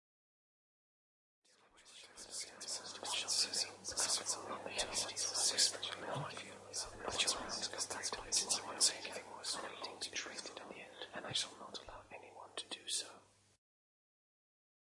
Звуки внутреннего голоса
Несколько внутренних голосов внутри меня